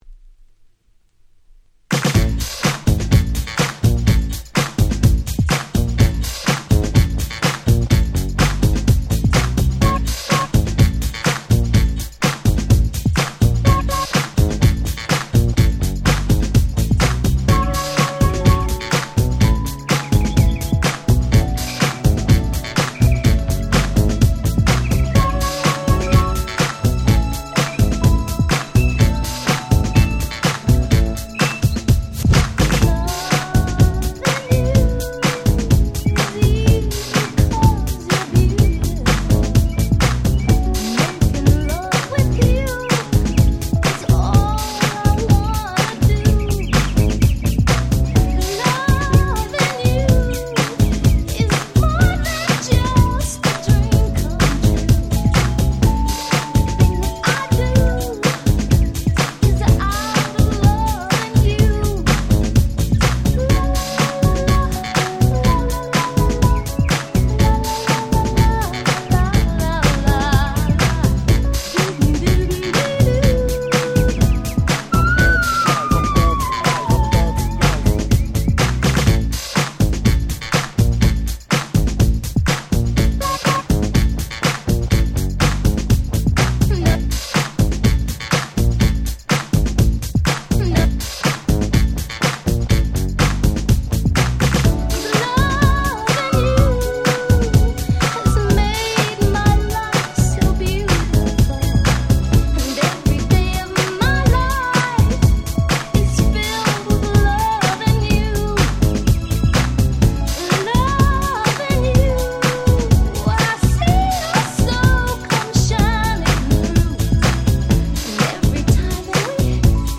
アップテンポなHip Hop Beatで非常にキャッチーな仕上がり！！